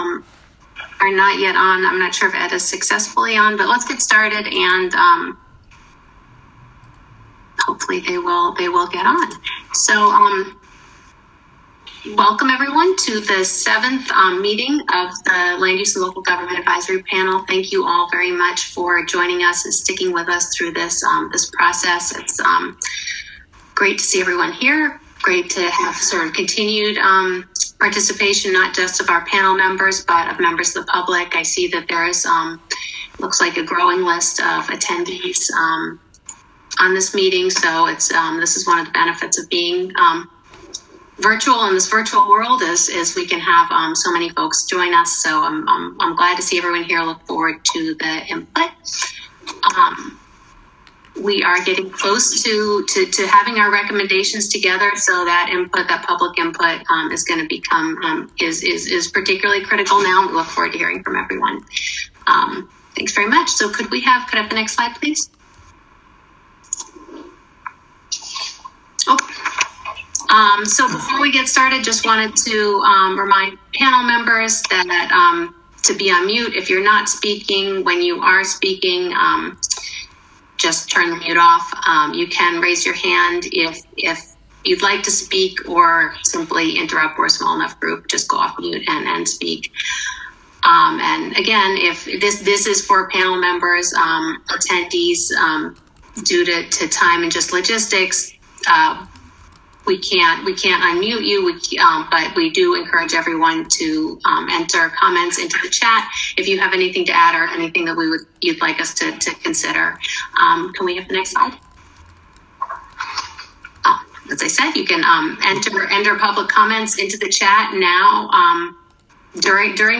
listen to recording of meeting